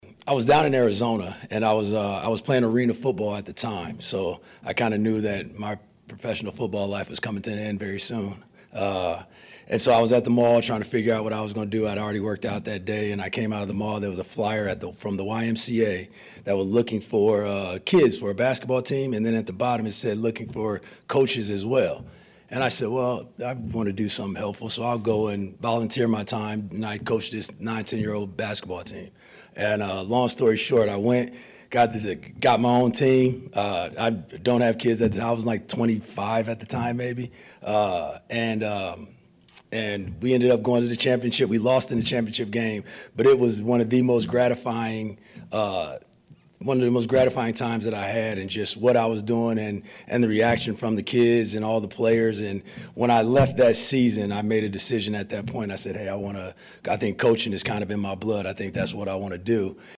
GREEN BAY, WI (WTAQ) – In advance of next week’s start to the Organized Team Activities, the Packers served up the three coordinators for a media session on Tuesday and today, it was the five assistant coaches on the defensive staff.